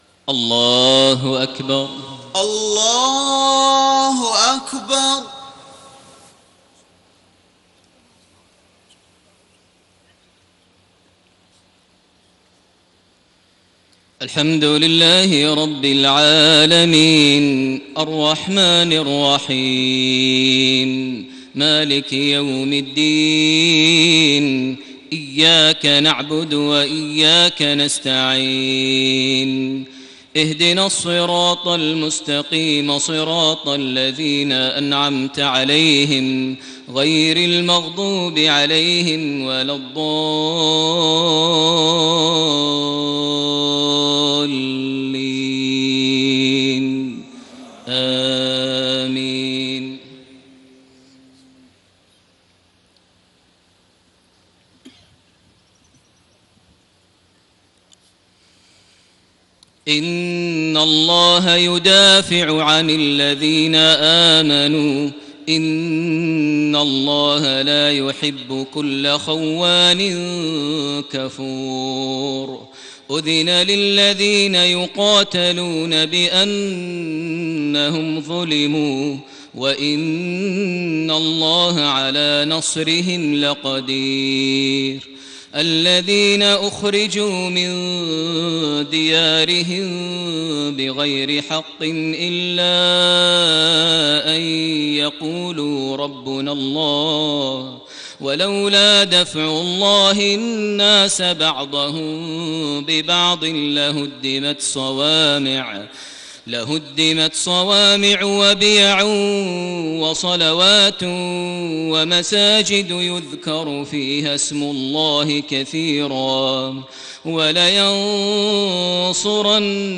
صلاة المغرب 23 شوال 1433هـ من سورة الحج 38-47 > 1433 هـ > الفروض - تلاوات ماهر المعيقلي